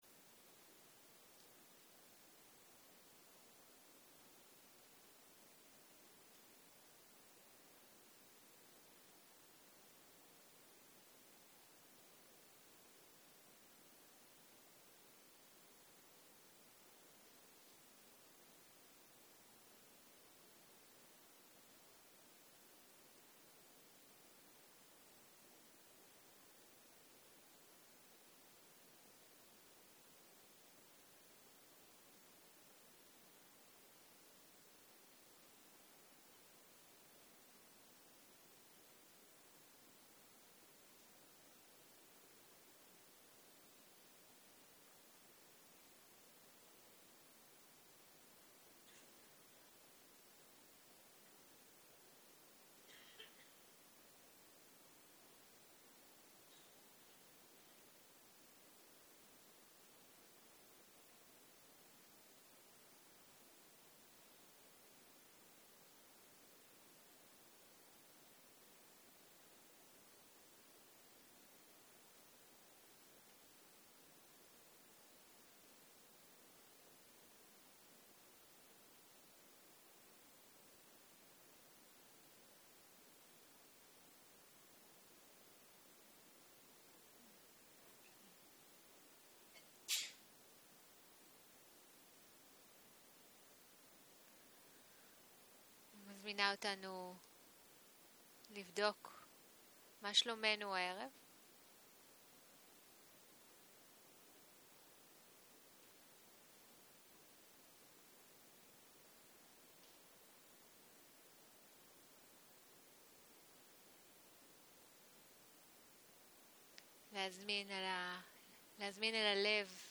יום 4 - ערב - מדיטציה מונחית - לאפשר לעצמנו לנוח - הקלטה 10
Guided meditation